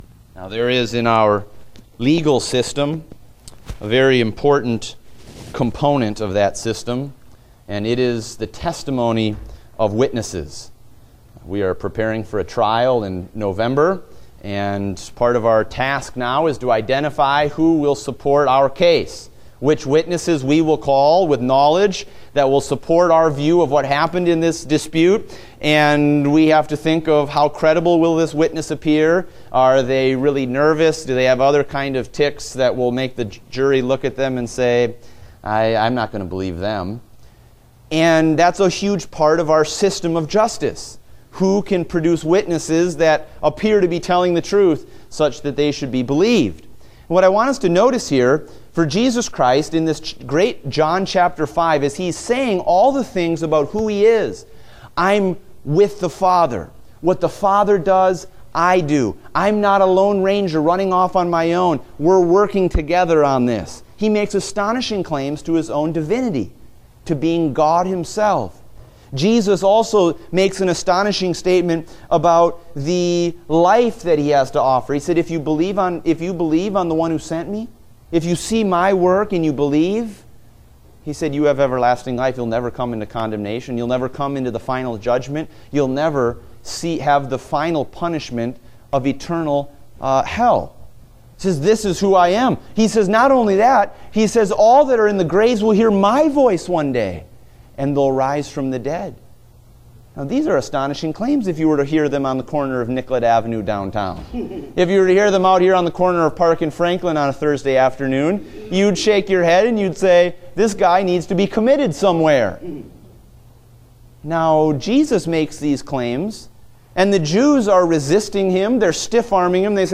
Date: July 31, 2016 (Adult Sunday School)